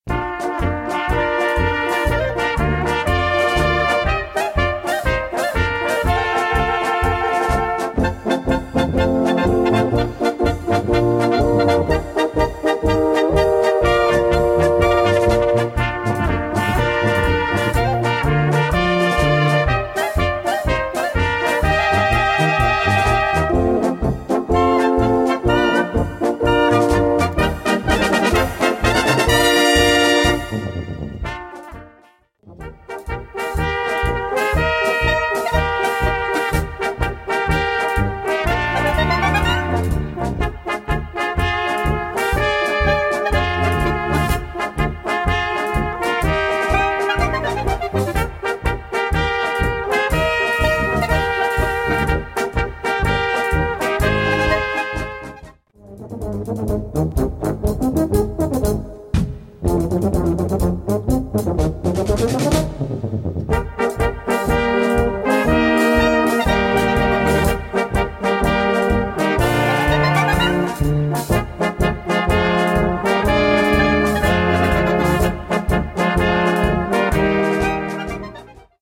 Eine gemütliche Polka